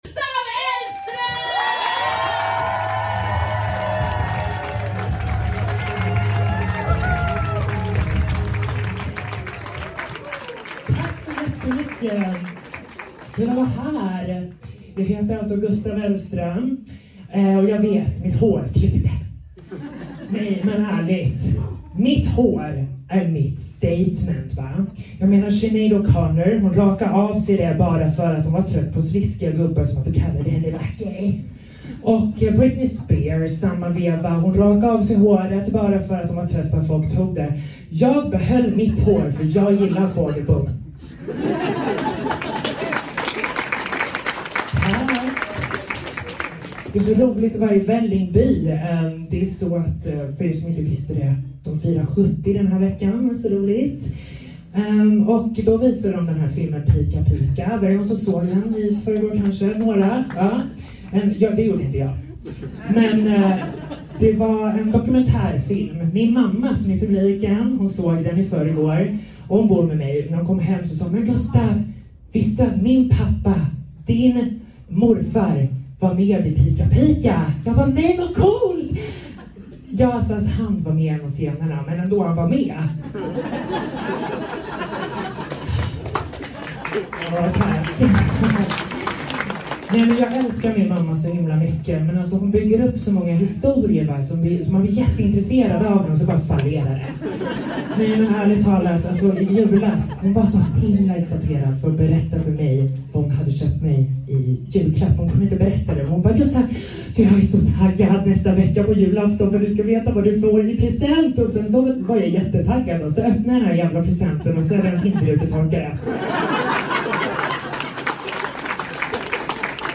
Igår var det exakt ett år sen jag gjorde standup för första gången när jag värmde upp scenen för Magnus Betnér på West Side Comedy i Vällingby. Jag har fått frågor från er om jag kan ladda upp inspelningen från den kvällen i nästan ett års tid.